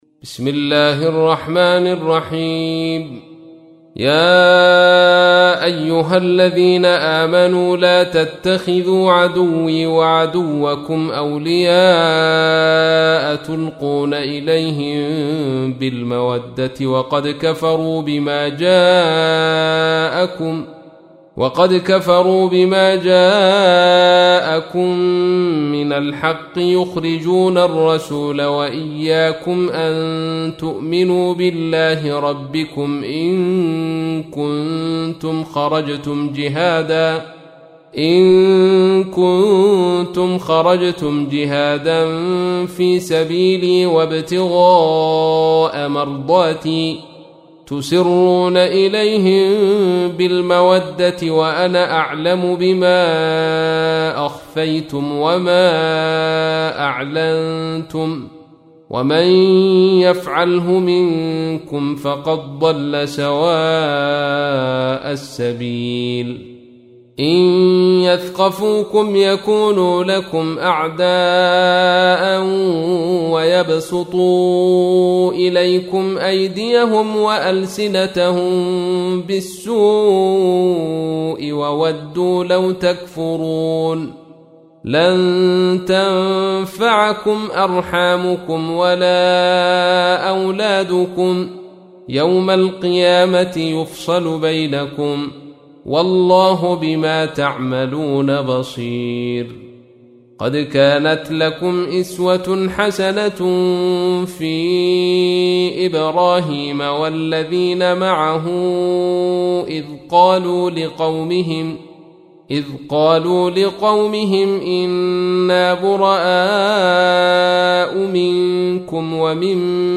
تحميل : 60. سورة الممتحنة / القارئ عبد الرشيد صوفي / القرآن الكريم / موقع يا حسين